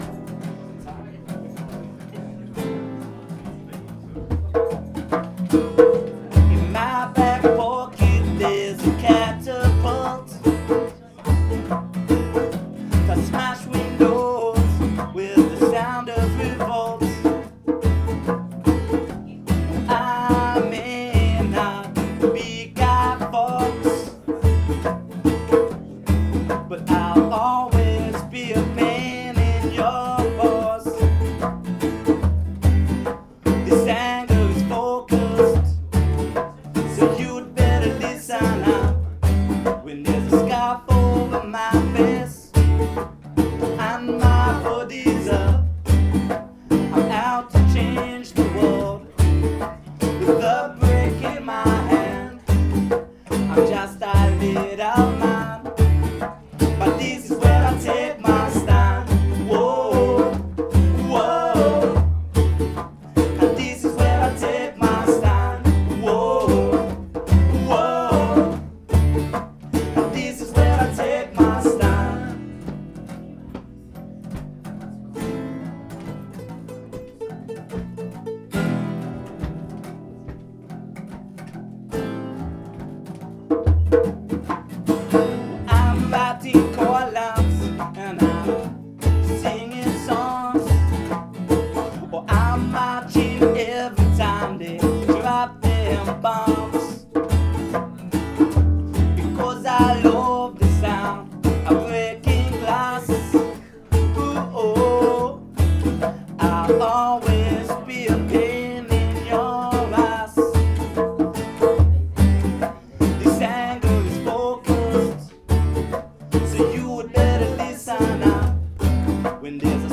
radio piece on Vortex